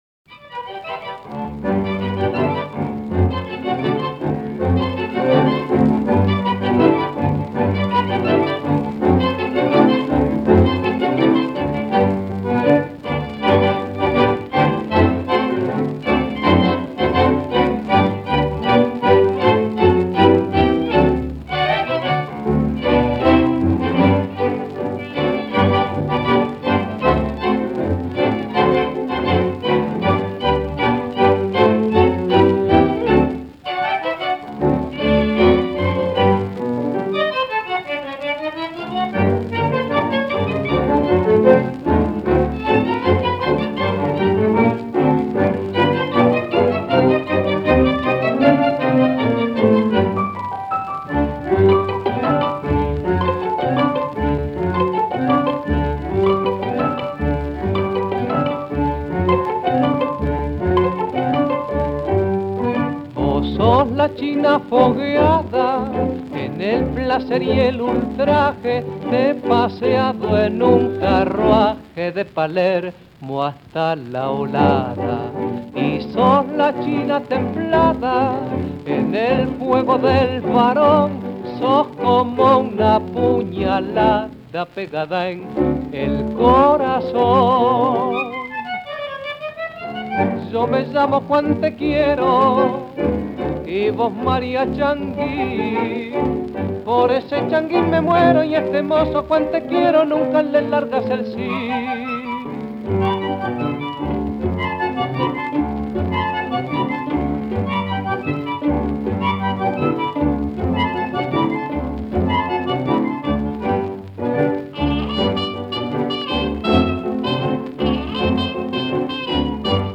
milonga